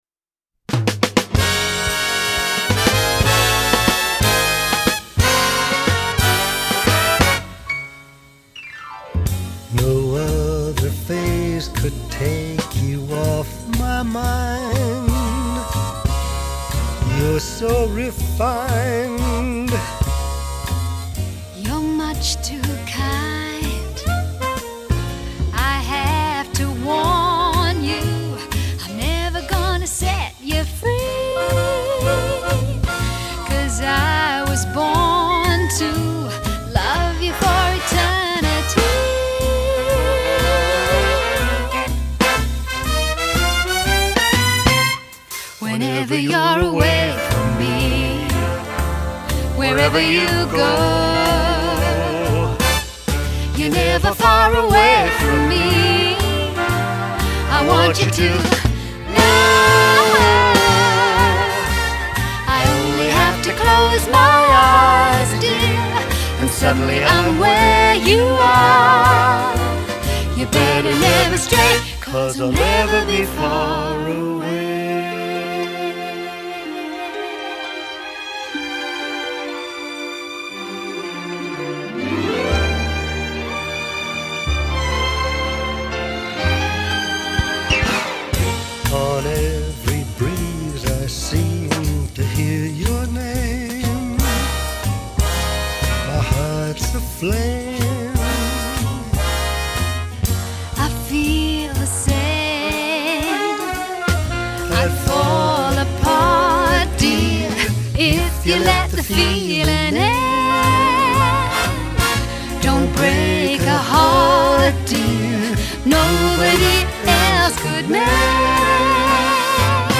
1980   Genre: Soundtrack    Artist